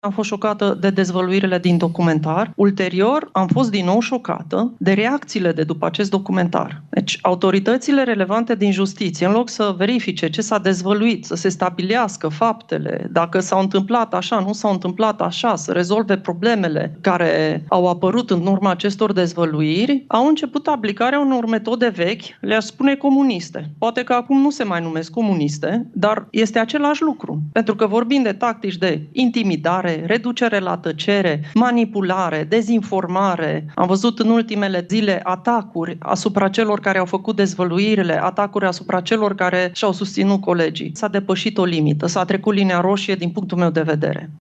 Șefa Parchetului European a spus într-un interviu pentru Friendly Fire, podcastul realizat de Vlad Petreanu și Moise Guran că a fost șocată de reacția autorităților române.